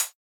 Index of /musicradar/retro-drum-machine-samples/Drums Hits/Raw
RDM_Raw_SY1-HfHat.wav